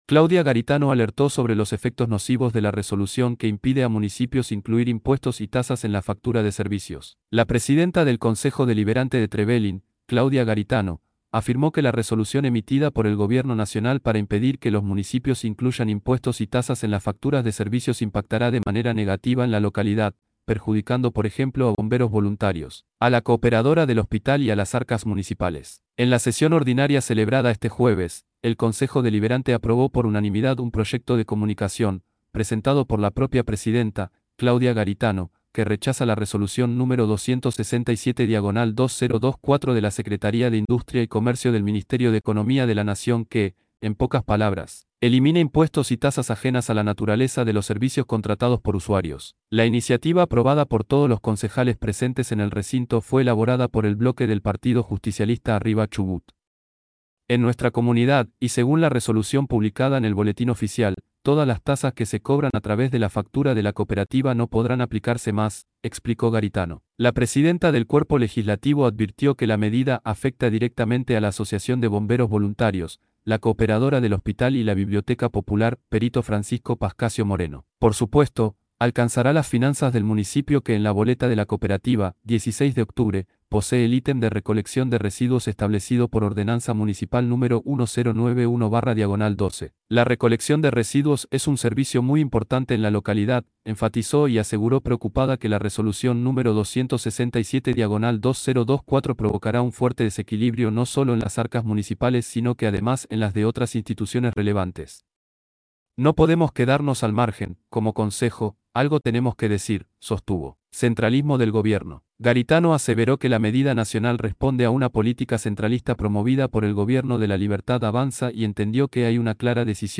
claudia_garitano_-_xi_sesion_2024_hcd_trevelin.mp3